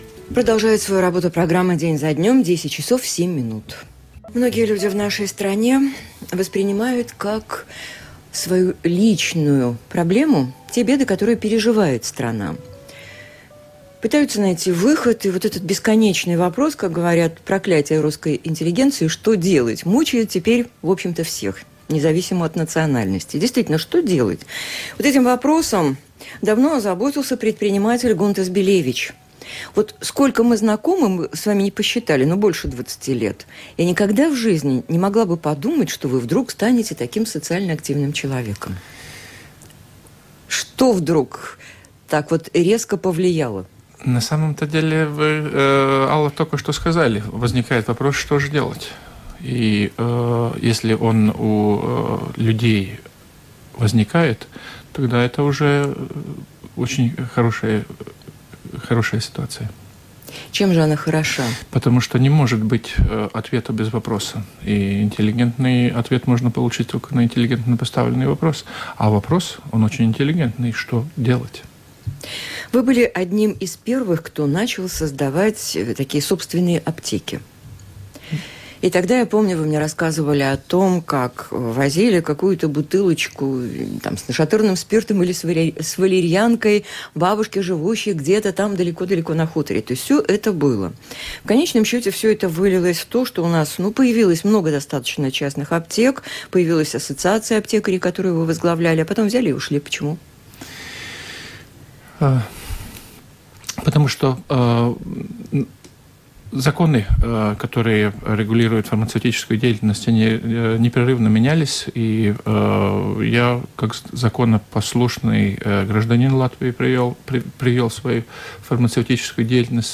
LR4: Diena pēc dienas, saruna ar Gunti Belēviču Latvijas Radio 4 – Doma laukums, Diena pēc dienas